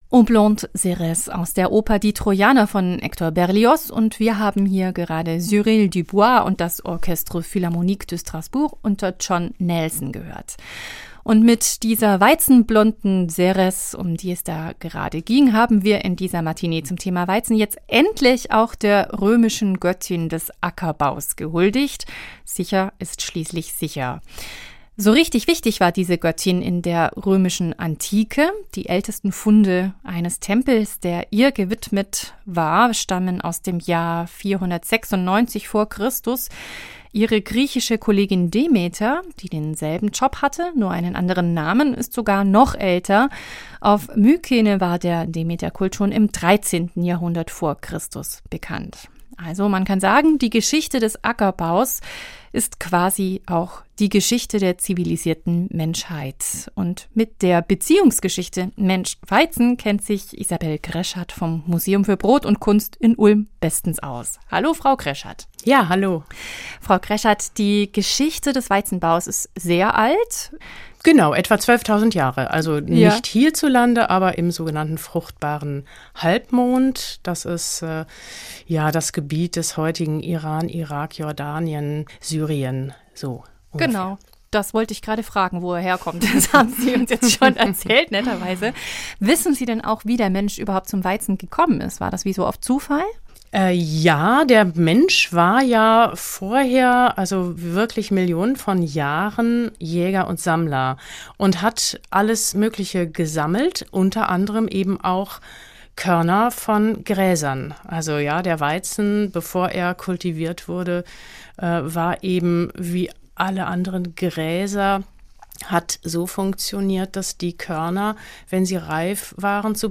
Ein Gespräch über eine lange und fast schon symbiotische Beziehung.